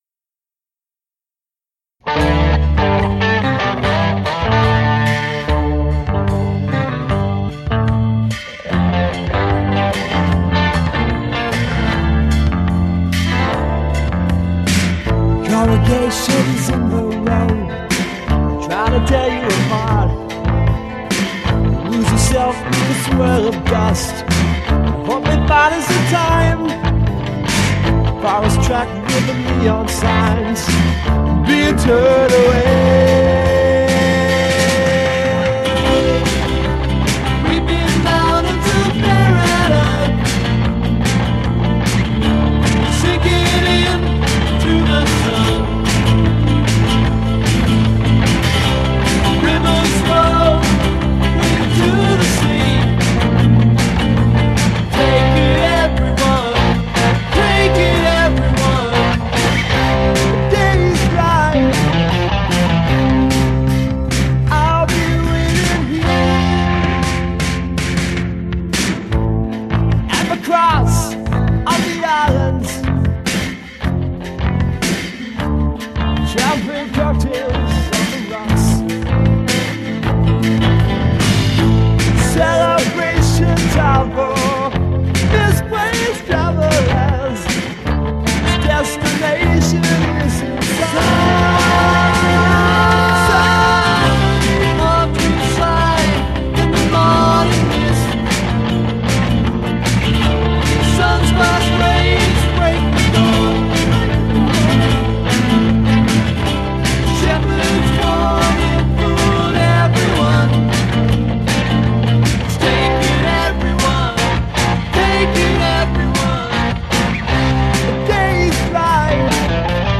vocals/bass
vocal/drums
keyboards